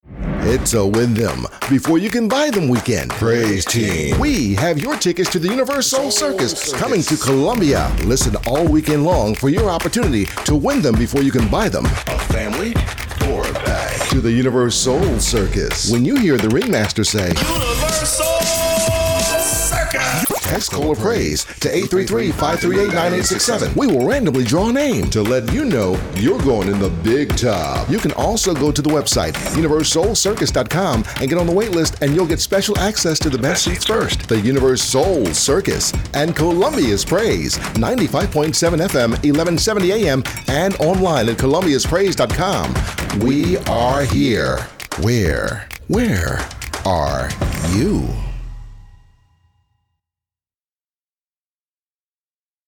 Jamaican, Southern, Corporate, Natural and mature
Middle Aged